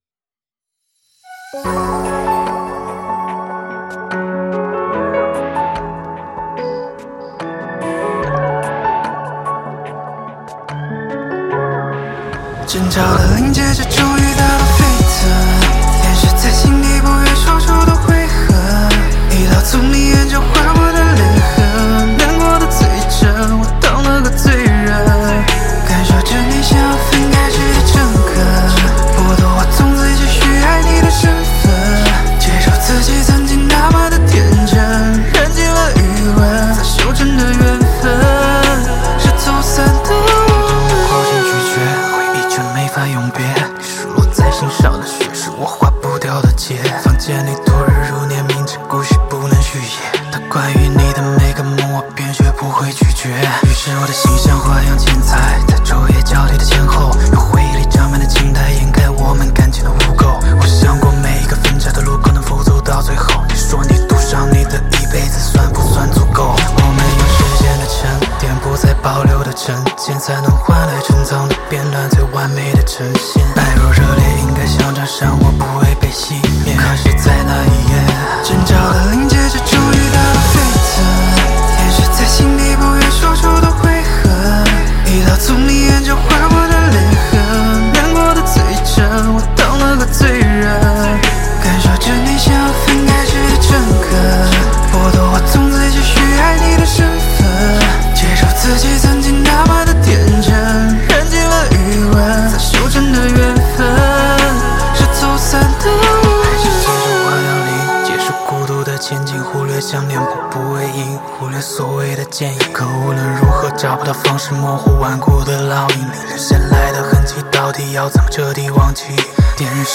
Ps：在线试听为压缩音质节选，体验无损音质请下载完整版 无歌词